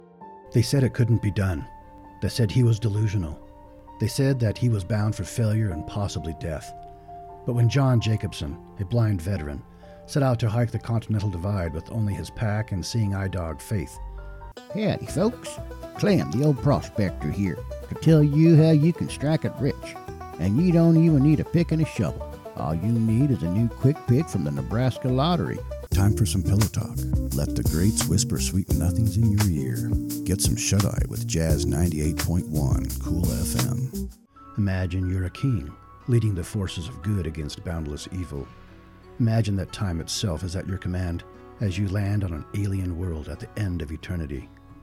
Demos
Accents and Dialects
I play around with Scottish/Irish accents, and some Eastern Indian.
Voice Age
Middle Aged
Senior